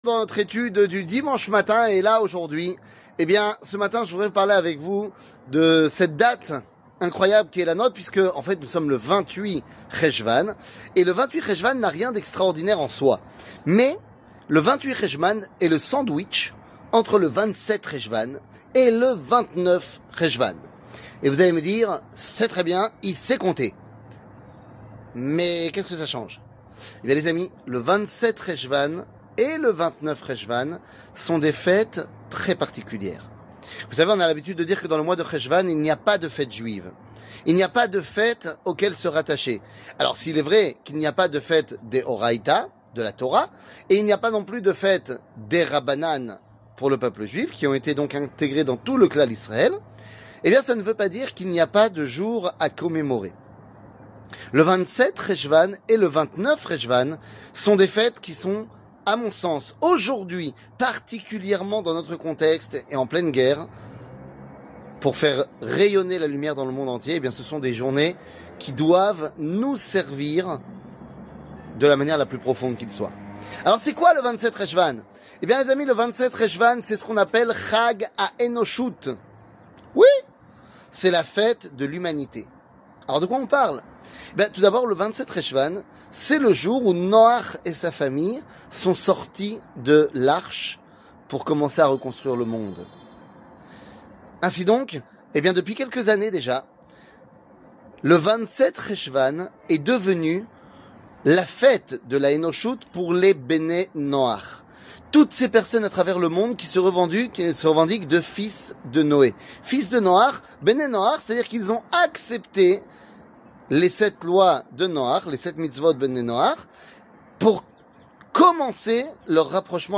27 et 29 Hechvan, Les fetes de l'espoir 00:04:43 27 et 29 Hechvan, Les fetes de l'espoir שיעור מ 12 נובמבר 2023 04MIN הורדה בקובץ אודיו MP3 (4.32 Mo) הורדה בקובץ וידאו MP4 (9.29 Mo) TAGS : שיעורים קצרים